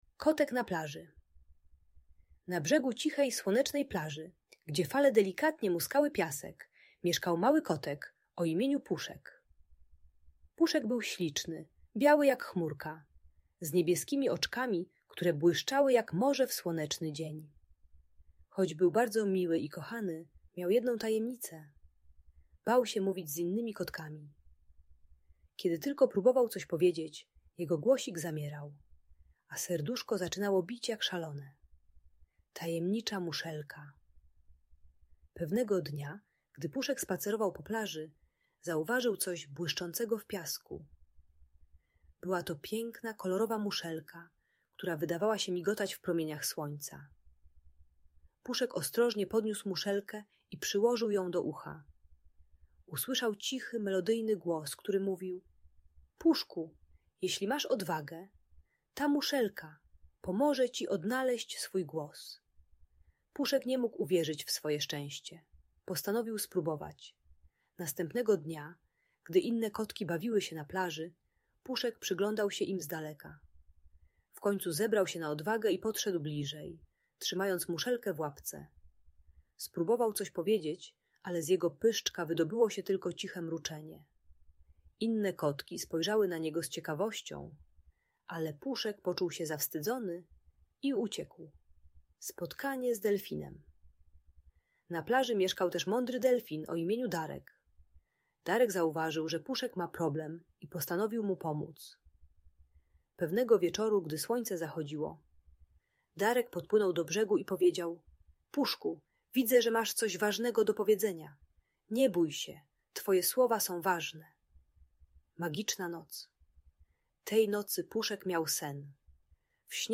Kotek Puszek na plaży - Lęk wycofanie | Audiobajka